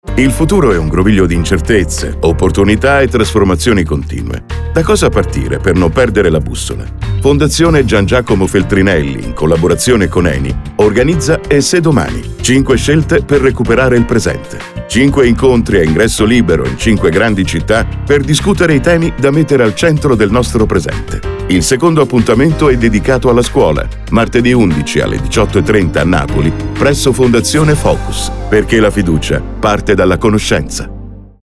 A sensual, captivating, deep, baritoneal, reassuring, incisive voice.
Sprechprobe: Werbung (Muttersprache):